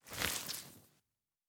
Wet_Snow_Mono_02.wav